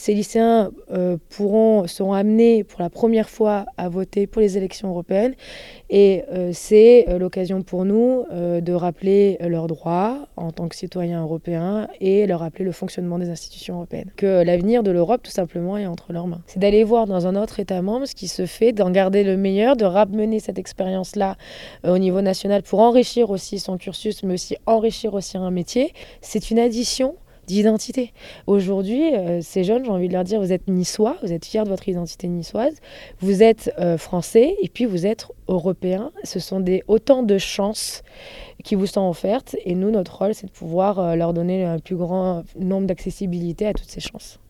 son-adjointe-au-maire-affaires-europeennes-4229.mp3